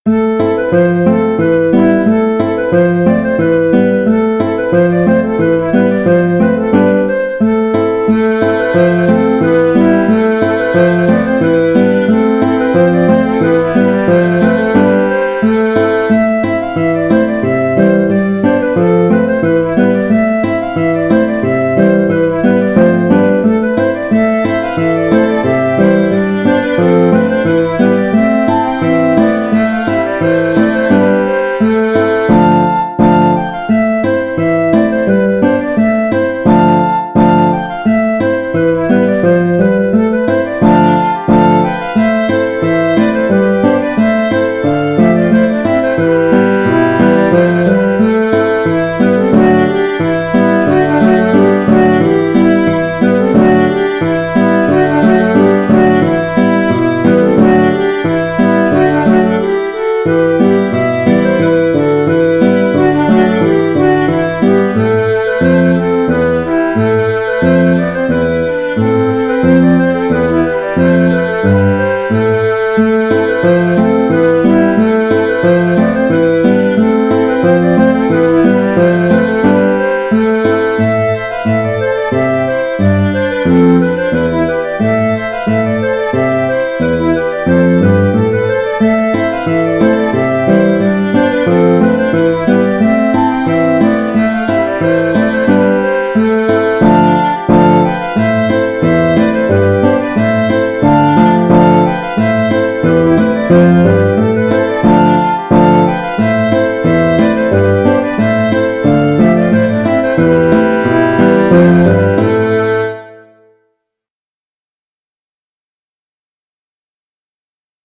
Hanter dro de la forêt
La mélodie est en trois parties, pour varier les plaisirs. Attention, le 3e thème change de tonalité.